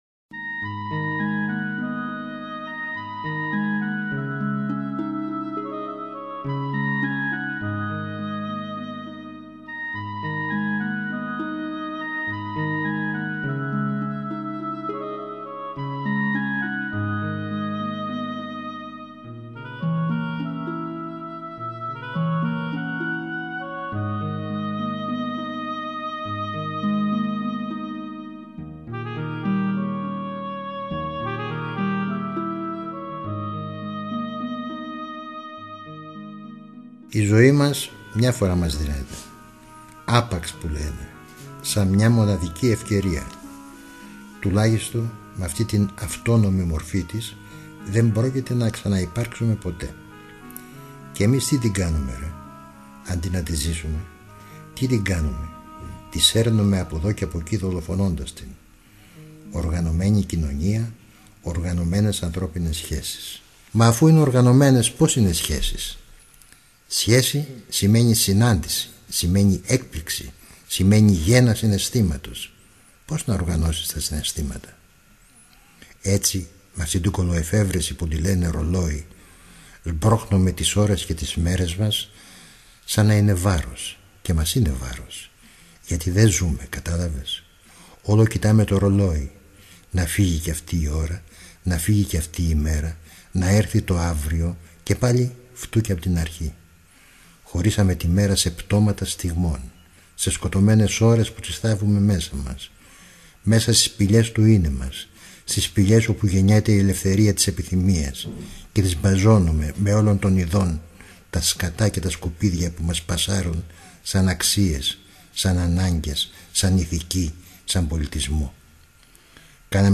Ο Χρόνης Μίσσιος διαβάζει Χρόνη Μίσσιο
Οι πρωτότυπες ηχογραφήσεις του Χρόνη Μίσσιου αποτελούν 17 ενότητες με τίτλους: